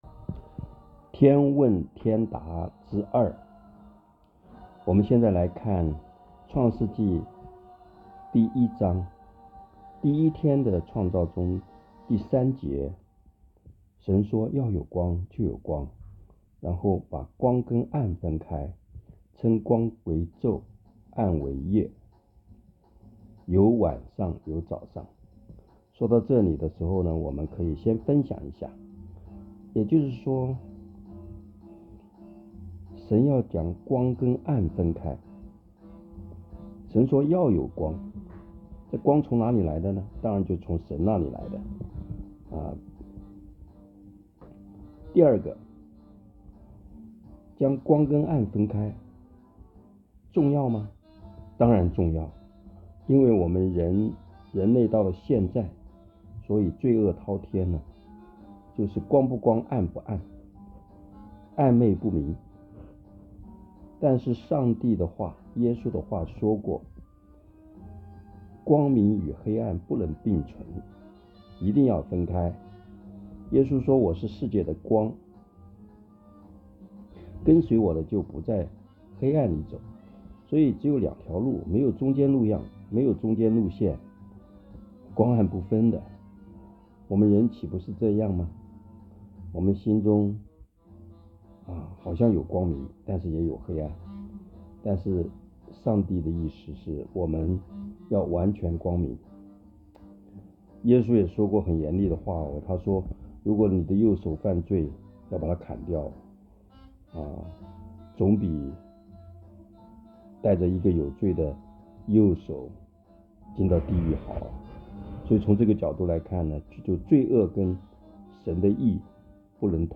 短讲